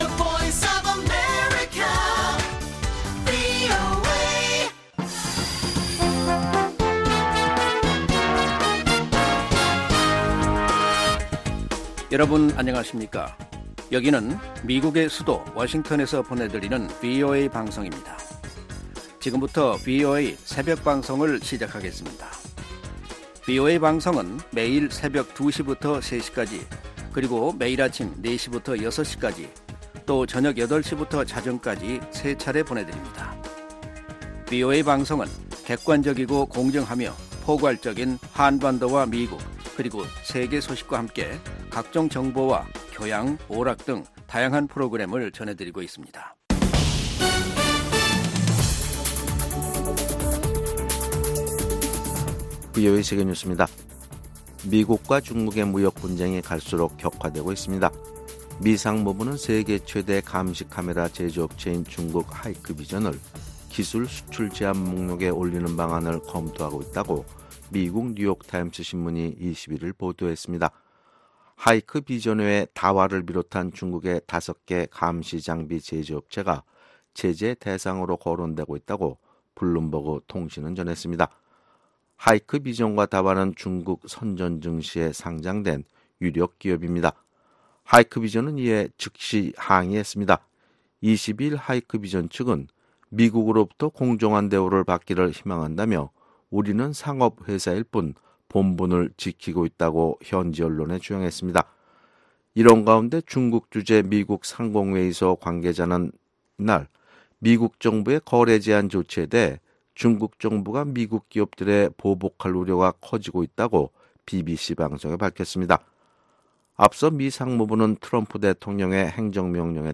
VOA 한국어 '출발 뉴스 쇼', 2019년 5월 23일 방송입니다. 올해 상반기 미국 의회에서 발의된 한반도 외교 안보 관련 법안과 결의안이 총 10건으로 북한 문제에 대한 미 의회의 지속적인 관심이 이어지고 있습니다. 유엔주재 김성 북한대사가 미국의 화물선 ‘와이즈 어네스트호’ 압류가 부당하다며 즉각적인 반환을 요구했습니다.